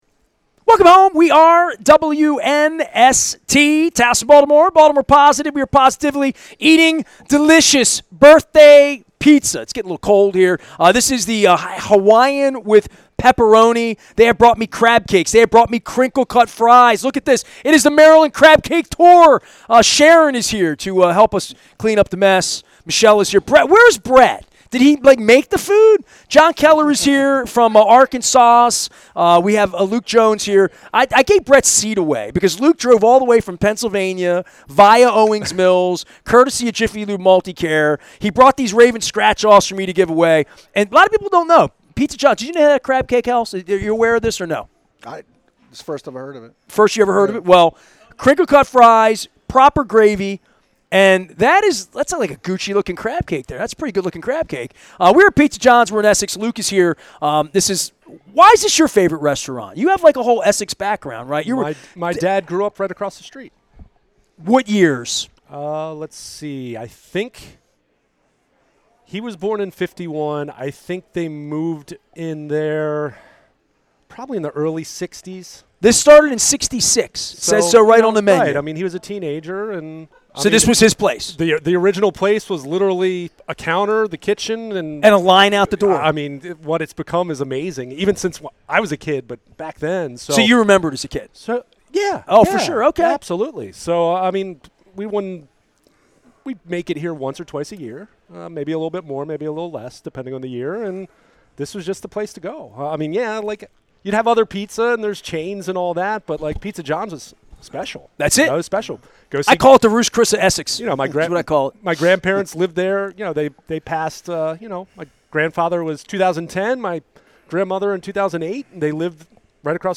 shared the crinkle cut french fries and gravy over a long conversation about the Orioles' future with fans in and out of market and growing the brand of Baltimore baseball